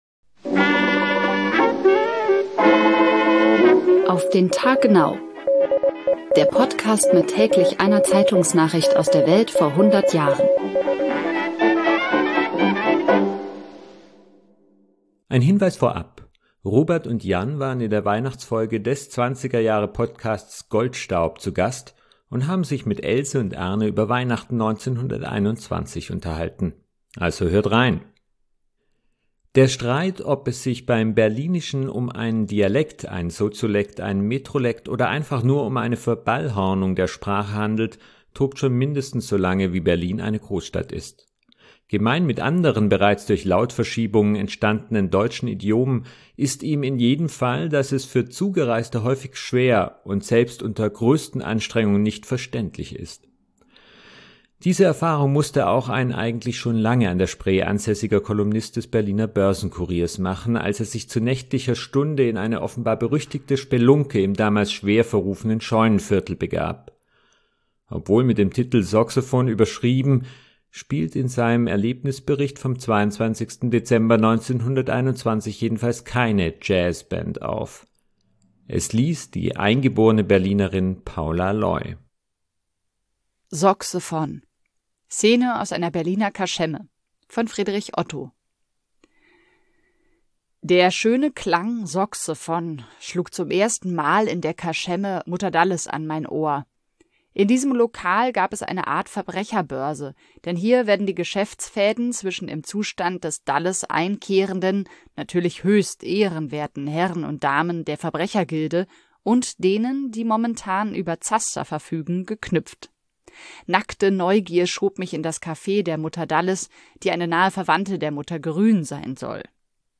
Es liest die eingeborene
Berlinerin